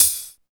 74 HAT.wav